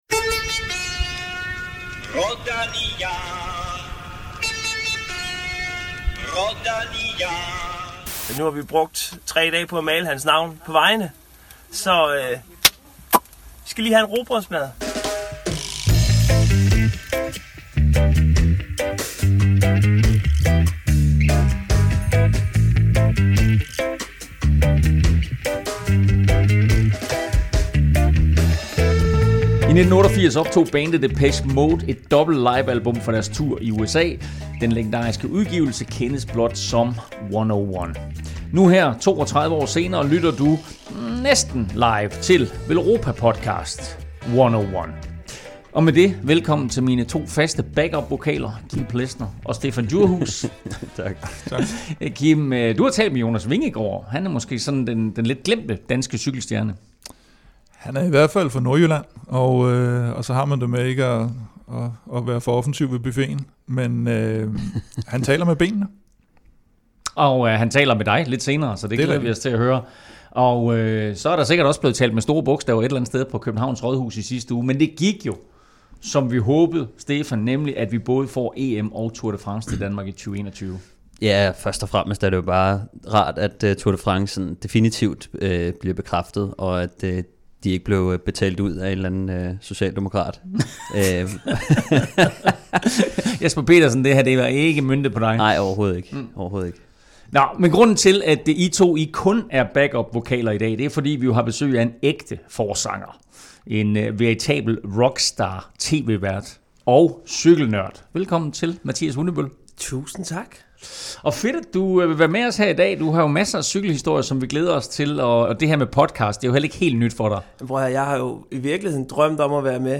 Vi har også et friskt interview med Jonas Vingegaard, der for nylig satte sin underskrift på en ny 2-årig kontrakt med storholdet Jumbo-Visma og som i år har Vueltaen som sit helt store mål.